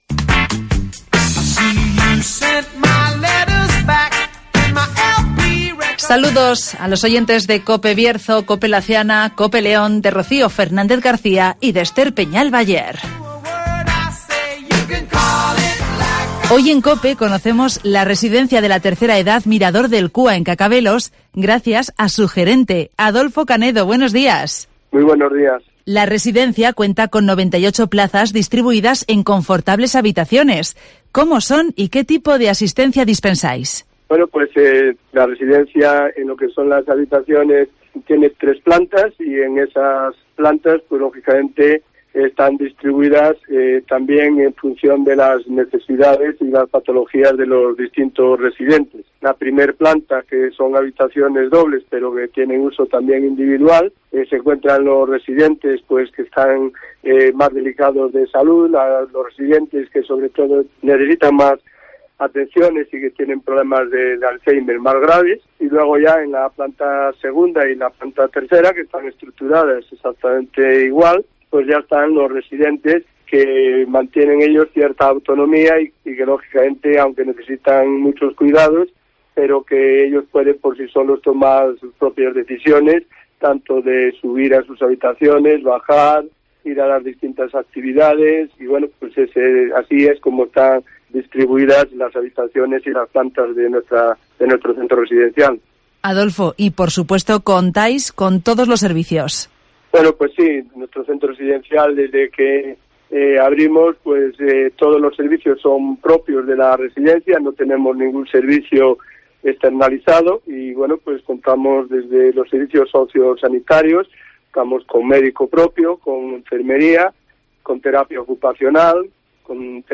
Conocemos la residencia de la tercera edad Mirador del Cúa en Cacabelos (Entrevista